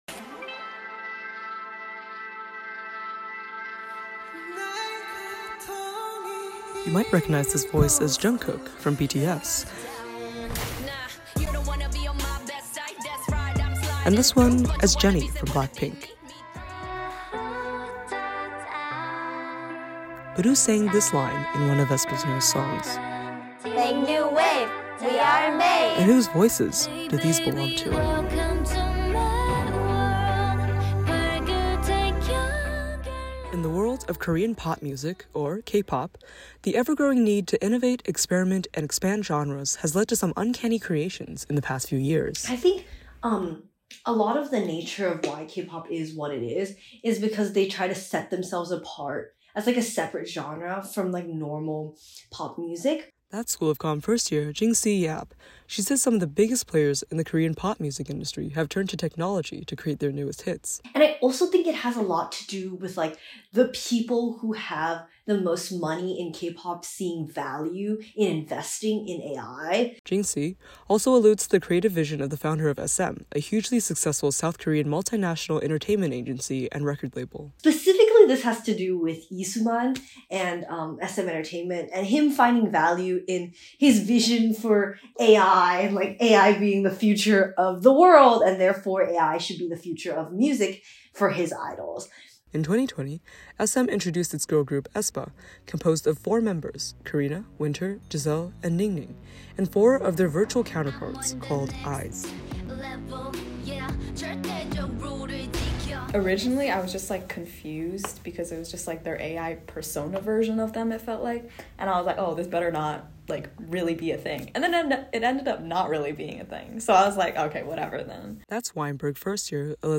This story originally aired as part of our A.I. Special Broadcast.